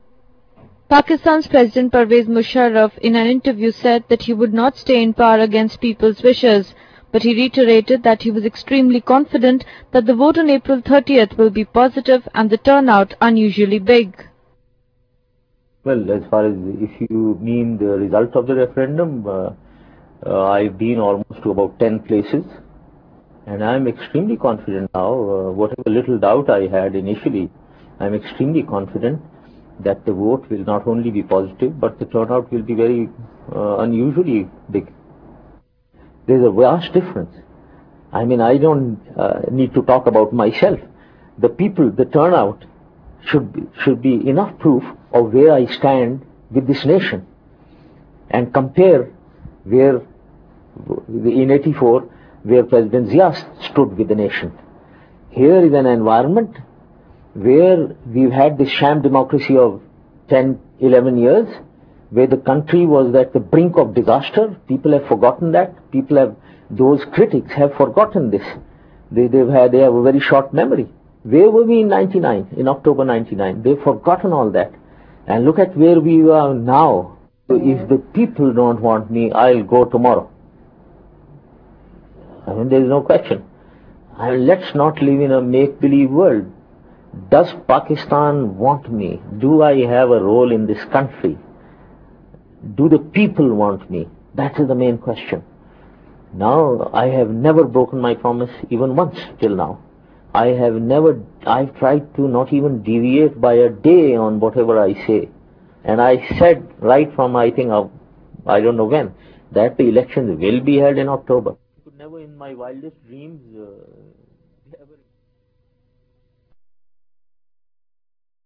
Pakistani military President Pervez Musharraf says that he would not stay in power against people's wish, but is "extremely confident" that the vote on April 30 will be positive and the turnout "unusually big."